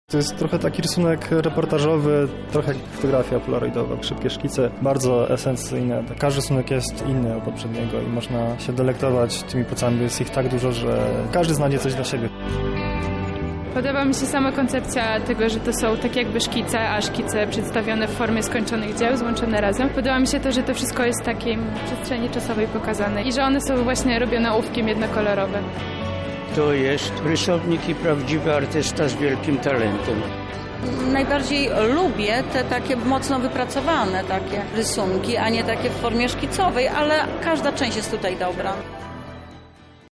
na wczorajszym wernisażu